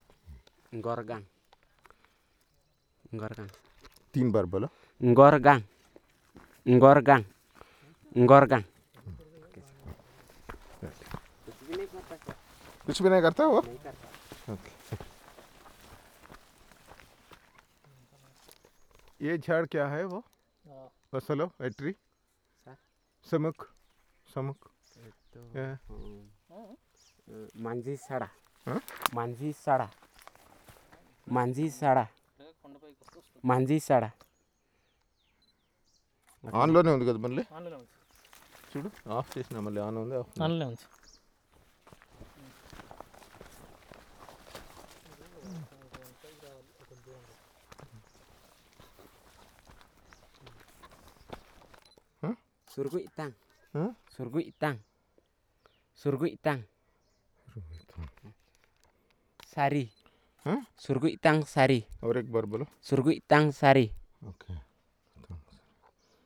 Elicitation of words on trees and shrubs